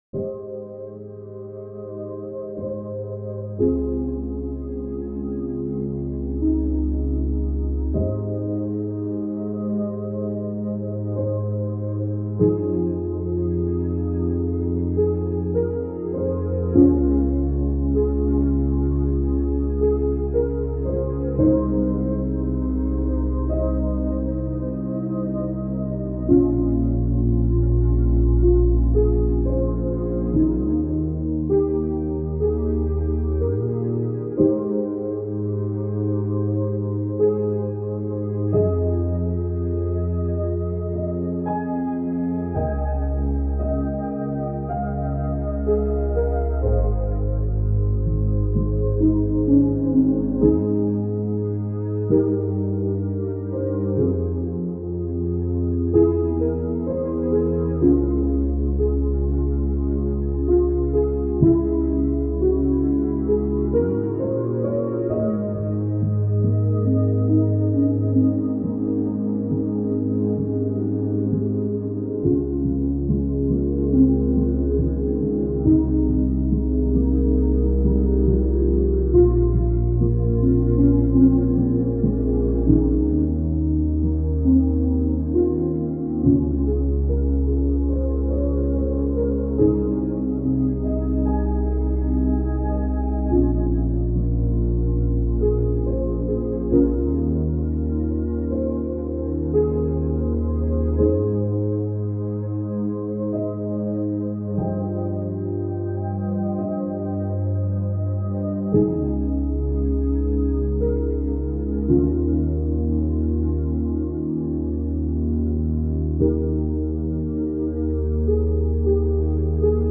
未分類 不思議 幻想的 浮遊感 穏やか 音楽日記 よかったらシェアしてね！